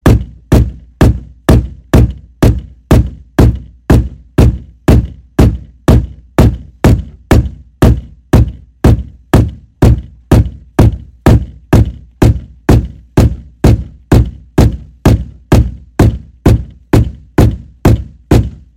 SINGLE-DEEP-FAST
SINGLE_-_DEEP_-_FAST.mp3